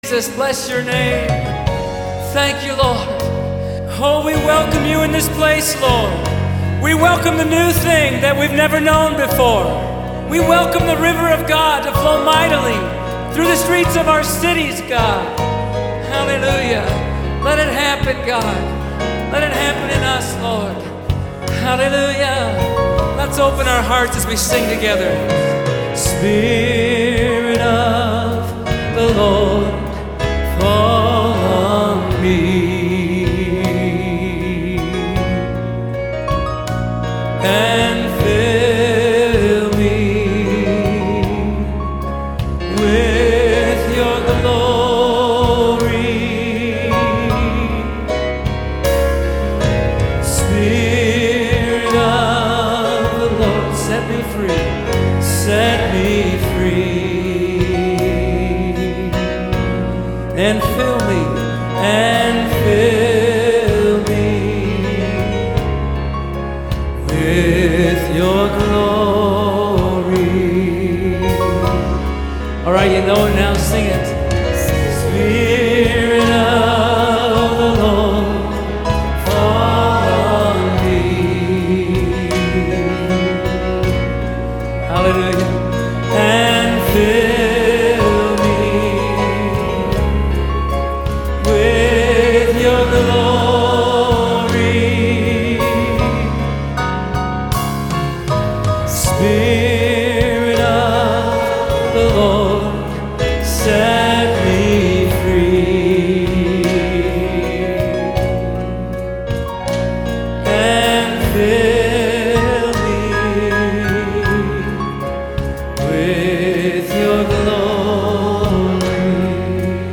worship song
and is very simple to sing.